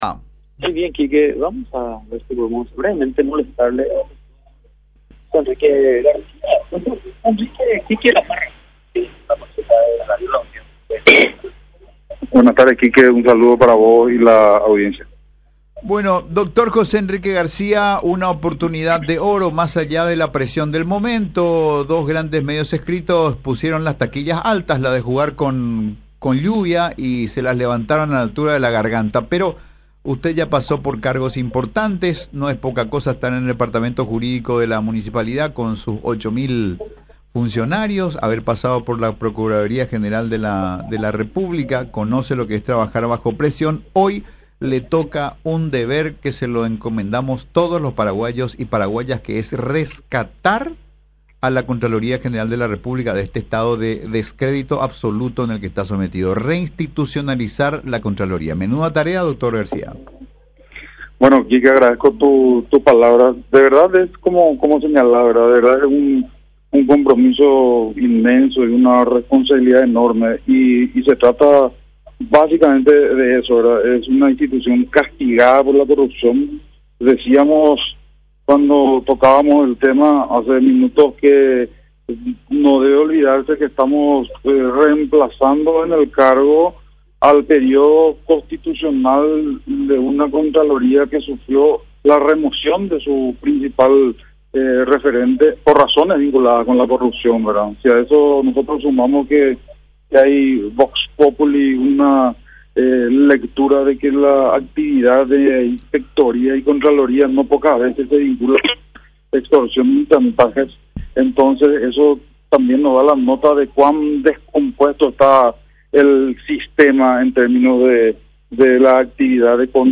El Dr. José Enrique García, nuevo contralor de la República habló en contacto con La Unión minutos después de su juramento. Se refirió al cargo que ocupa y cómo se desempeñaría: “Muchas veces se vinculó a la Contraloría como elemento de extorsión, y por eso es fundamental el reposicionamiento”